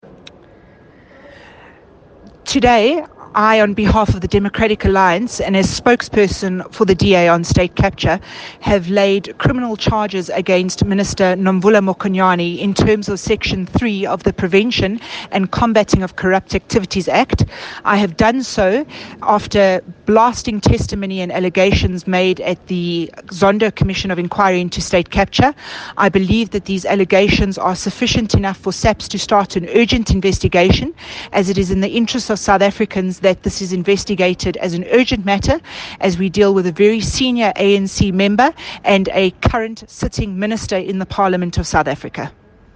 Note to Editors: Please find attached a soundbite in
English by Natasha Mazzone MP.